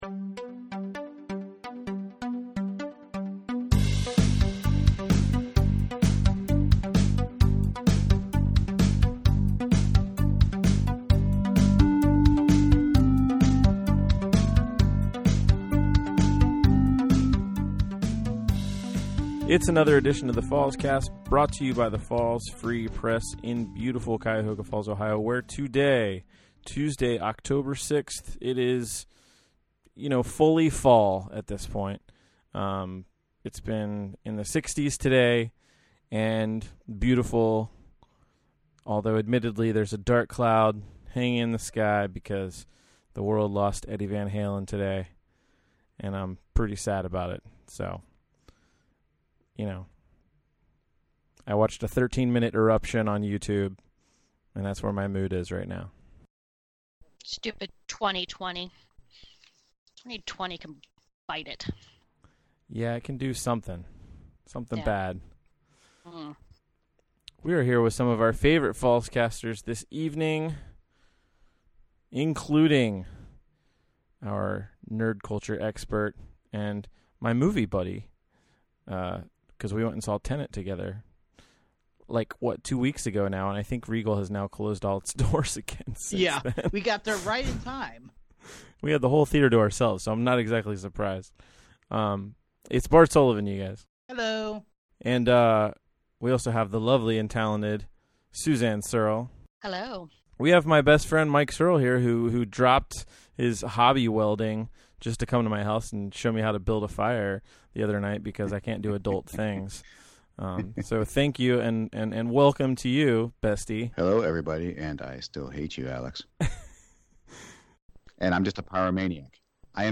the Falls Free Press staff discuss: